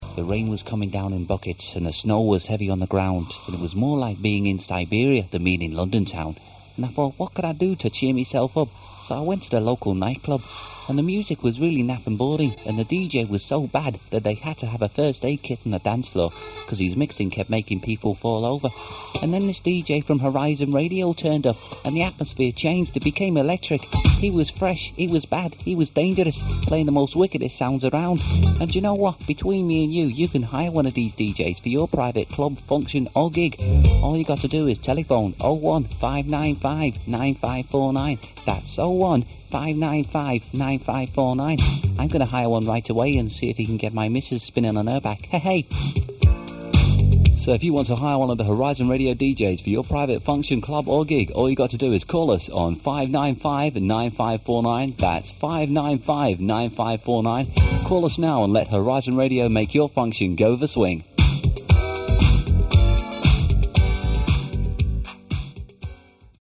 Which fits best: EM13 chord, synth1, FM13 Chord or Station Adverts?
Station Adverts